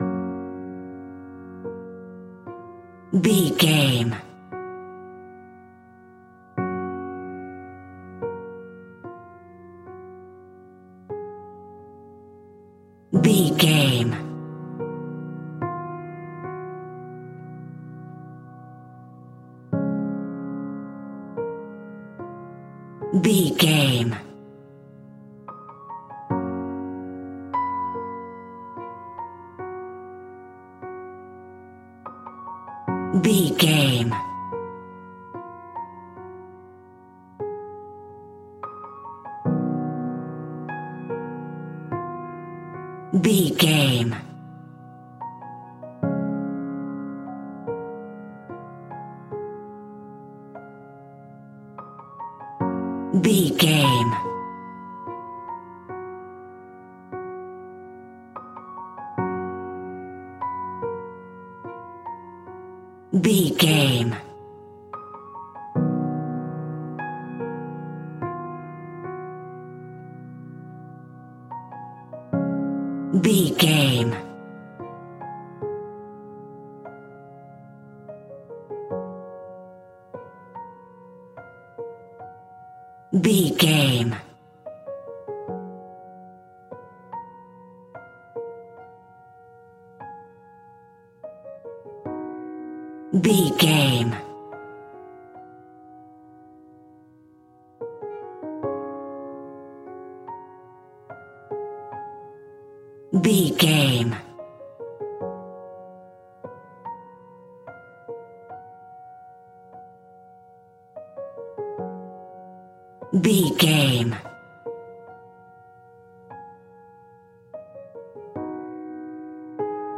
Peaceful and relaxing piano music.
Regal and romantic, a classy piece of classical music.
Ionian/Major
G♭
soft